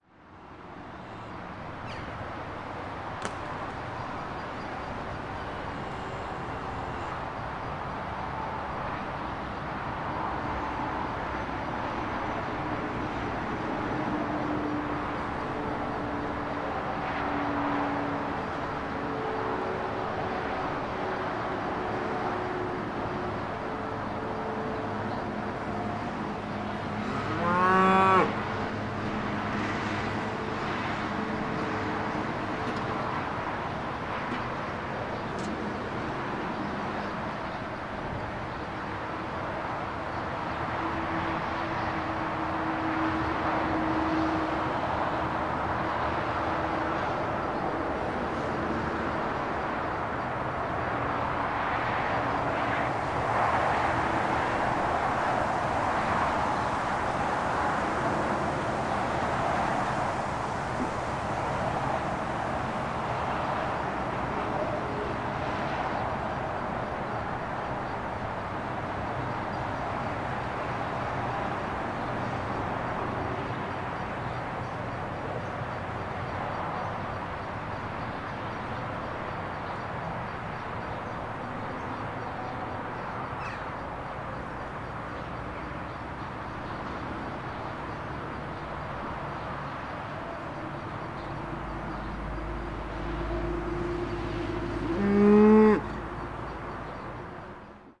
Oorkanaal Field Recordings " Koeien, R4 en riet Lichterveldestraat
描述：奶牛准备好喂食，而风吹过附近的树叶，增加了一些淡淡的白色噪音。 R4背景下的交通拥挤得到了和平的支持。 这张录音是在2014年7月14日下午在Desteldonk（根特）的Roland R26上用Sanken CS3e霰弹枪进行的。编辑在Reaper中完成。没有应用压缩。